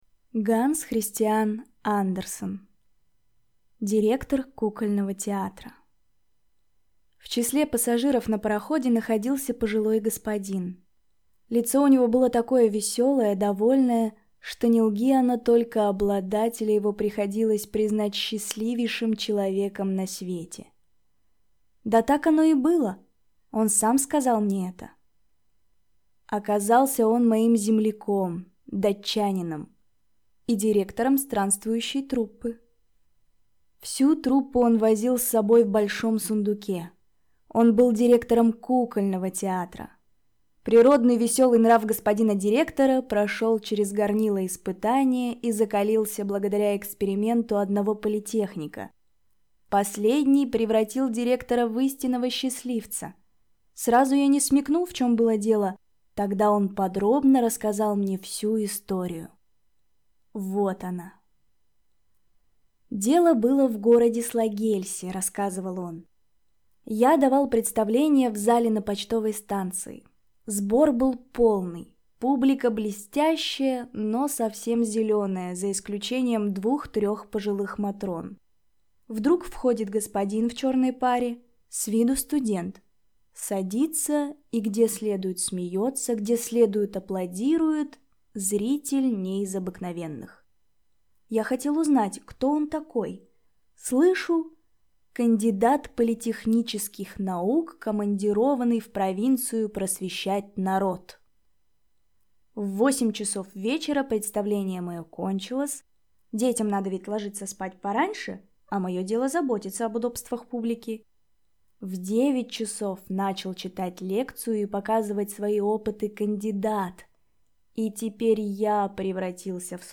Аудиокнига Директор кукольного театра | Библиотека аудиокниг